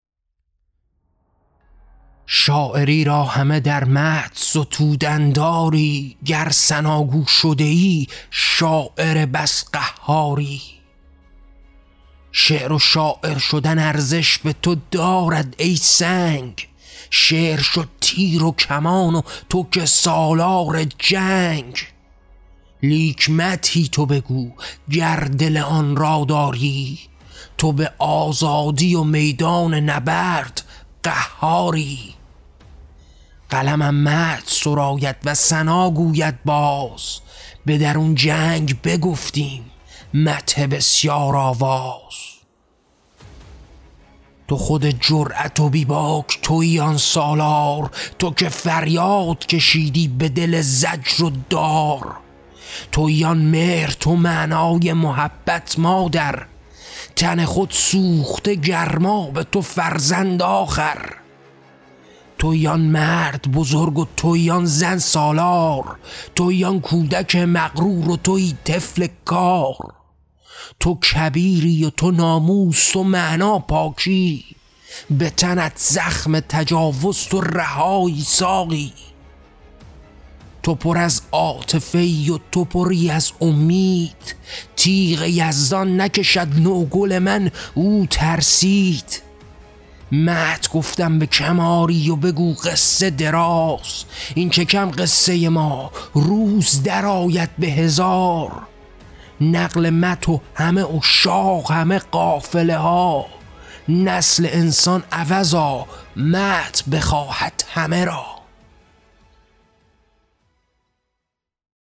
کتاب قیام؛ شعرهای صوتی؛ قسمت مدح: بازتعریف مقاومت و ستایش هویت انسانی